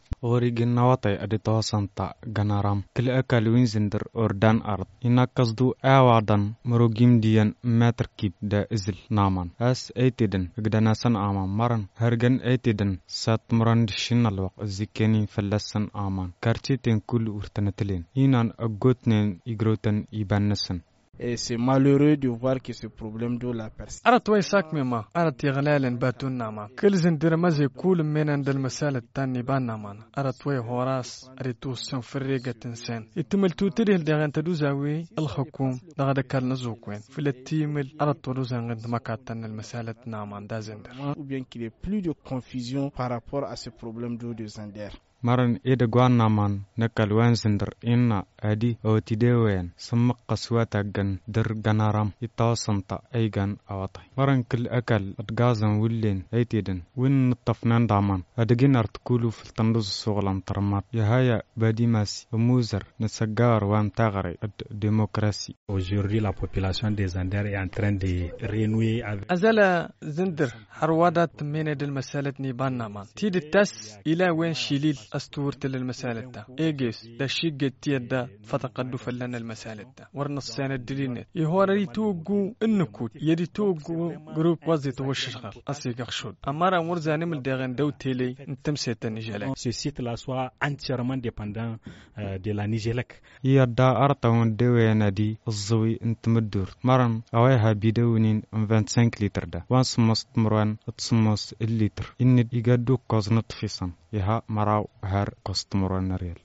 Quelles sont les conséquences de cette situation pour les foyers ? Enquête